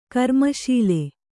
♪ karmaśile